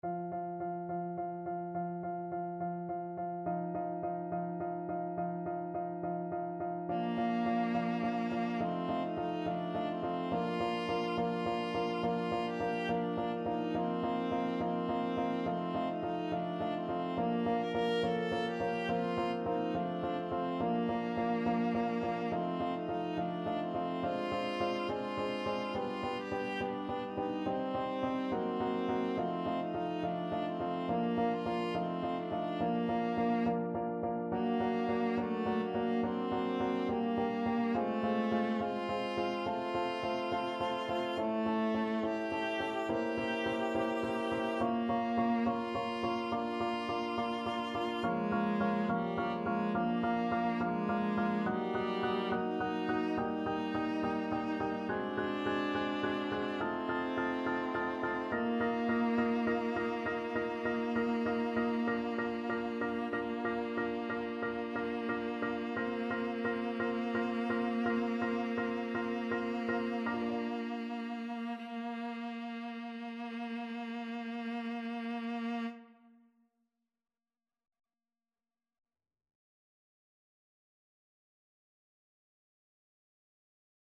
4/4 (View more 4/4 Music)
Andante maestoso
Classical (View more Classical Viola Music)